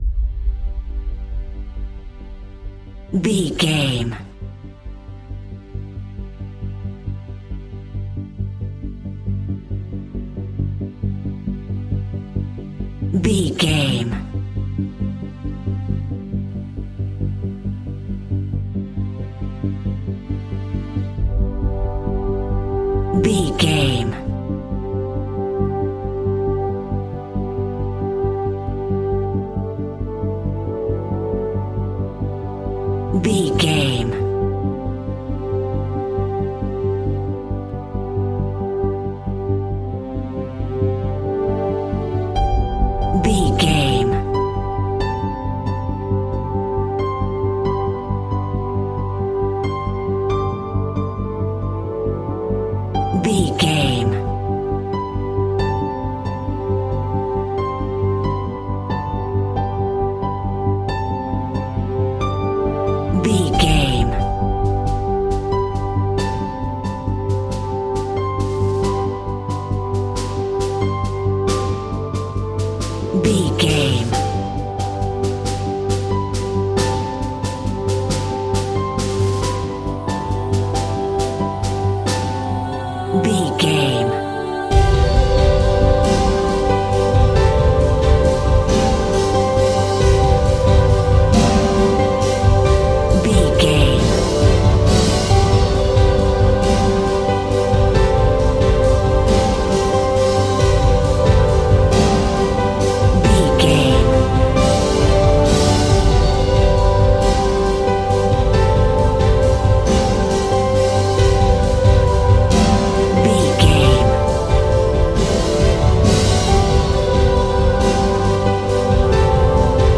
Ionian/Major
strings
percussion
synthesiser
brass
cello